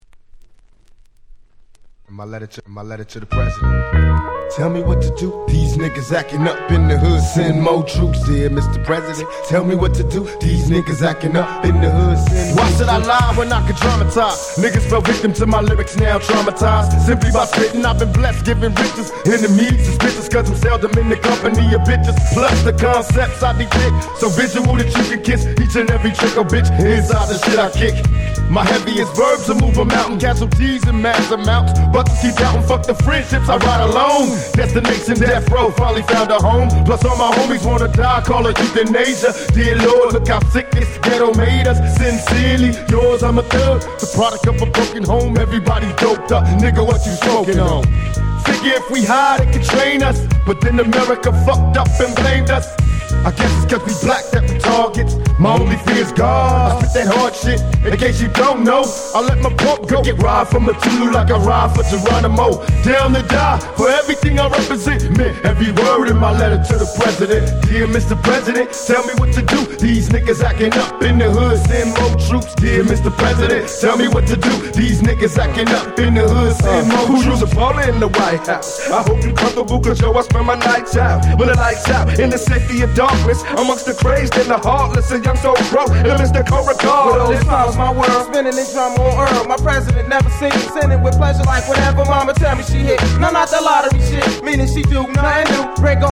98' Nice West Coast Hip Hop !!
90's G-Rap Gangsta Rap